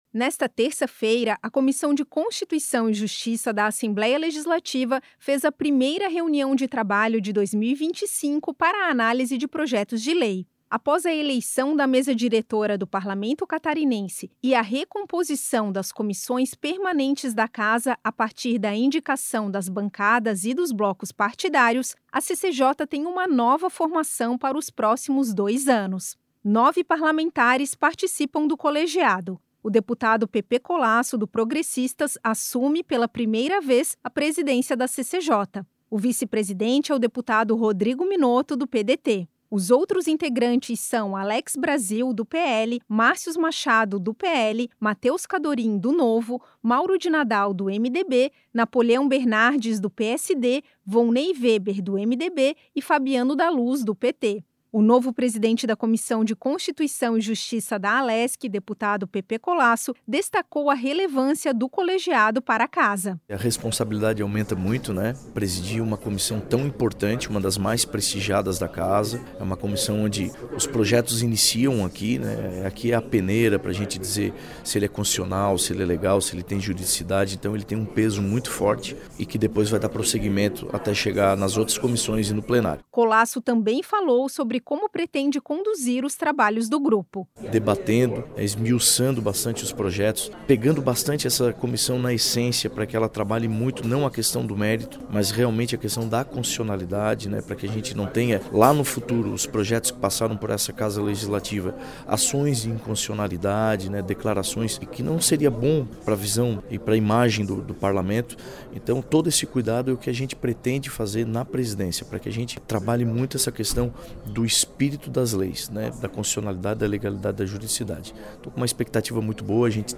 Entrevista com:
- deputado Pepê Collaço (PP), presidente da CCJ da Alesc.